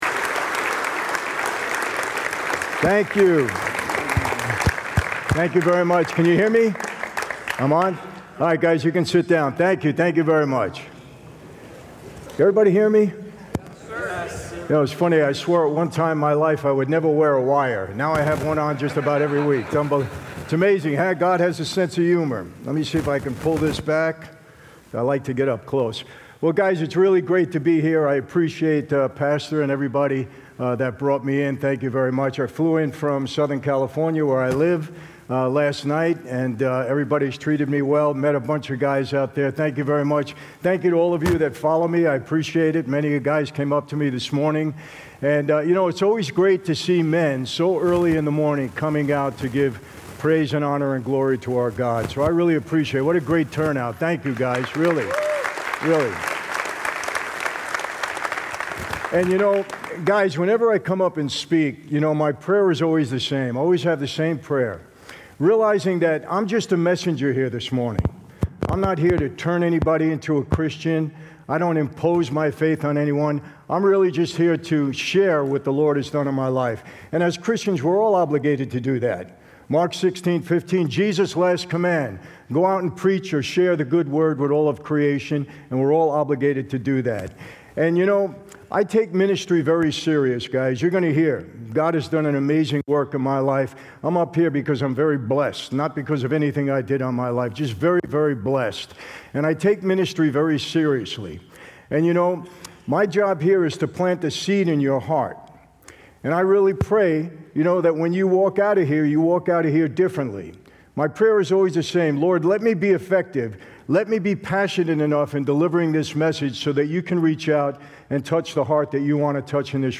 Men's Conference 2025 - From Mob Boss to Spiritual Gangster Oct 4 · Michael Franzese Listen to Michael Franzese teach at the Men's Conference in Tucson, Arizona in 2025.